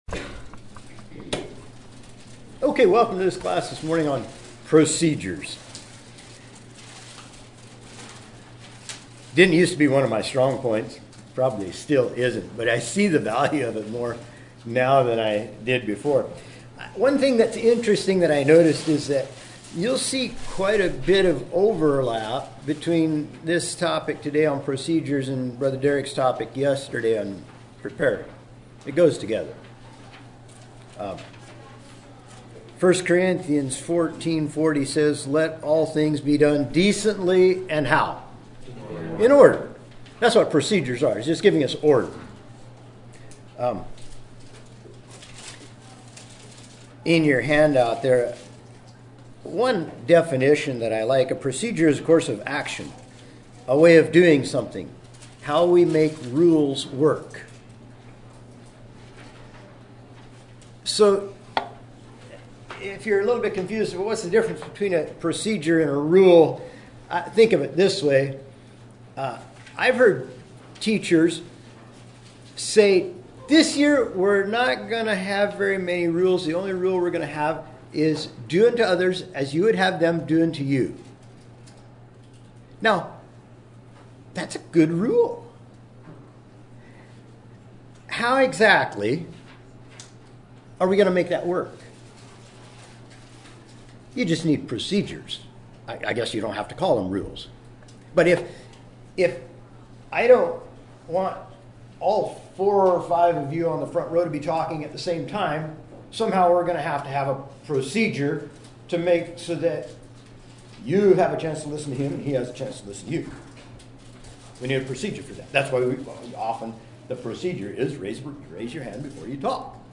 2025 Western Fellowship Teachers Institute 2024 Procedures 00:00